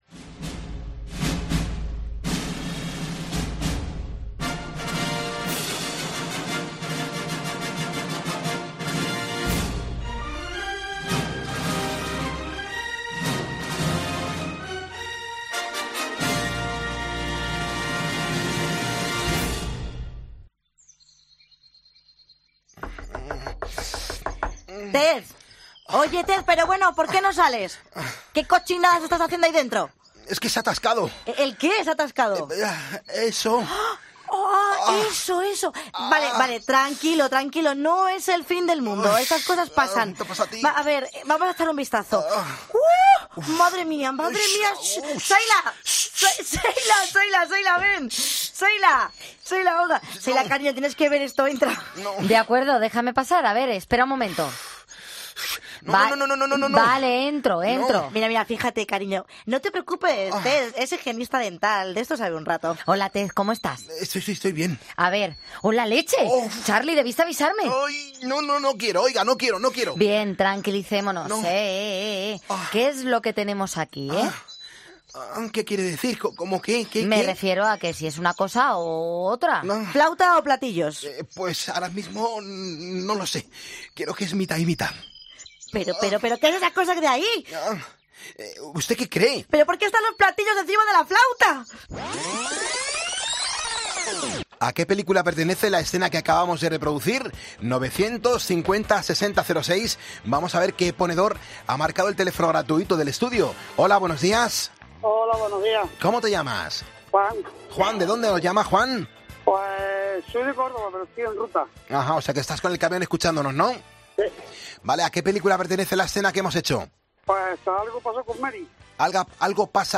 Interpretamos una escena de una película y vosotros ponedores la adivináis.